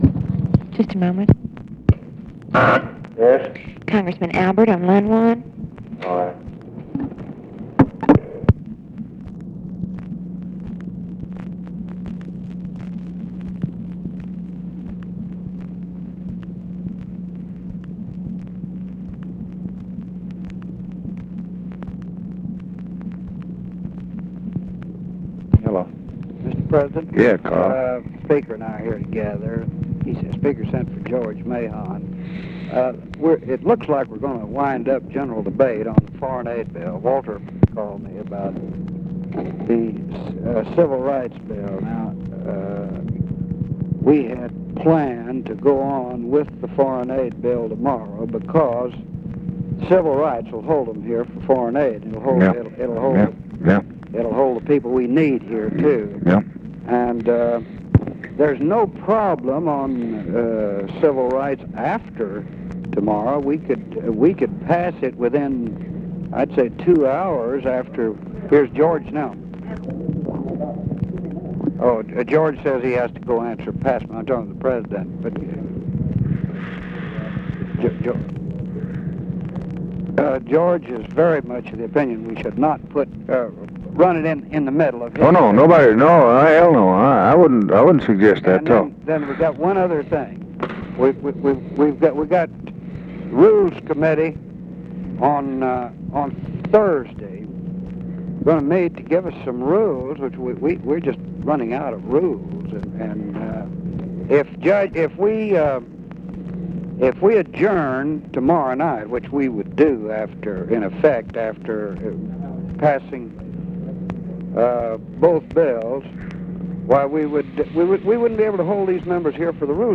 Conversation with CARL ALBERT, June 30, 1964
Secret White House Tapes